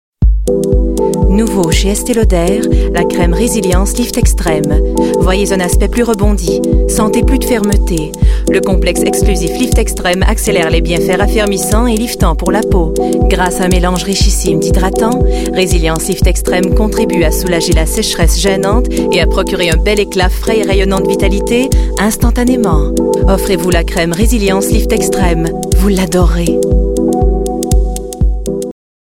Publicité (Estée Lauder) - FR